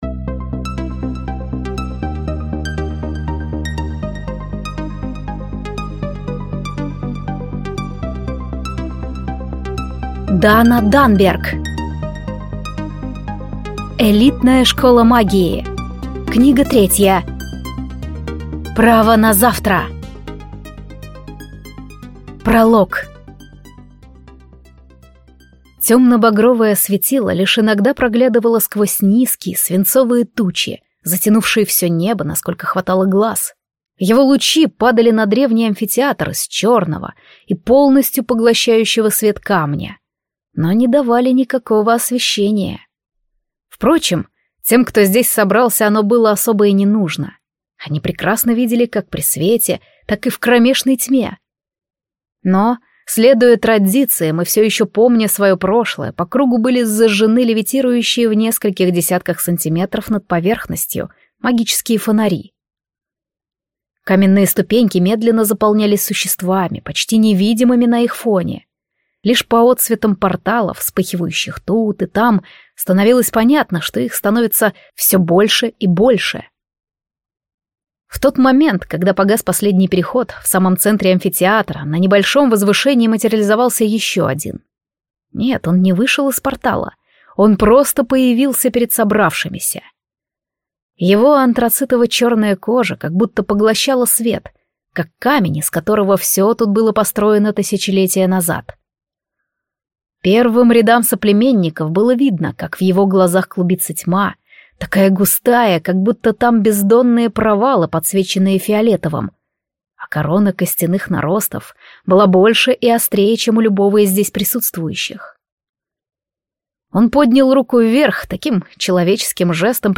Аудиокнига Элитная школа магии. Книга 3. Право на завтра | Библиотека аудиокниг